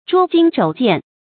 捉襟肘見 注音： ㄓㄨㄛ ㄐㄧㄣ ㄓㄡˇ ㄒㄧㄢˋ 讀音讀法： 意思解釋： 見「捉衿見肘」。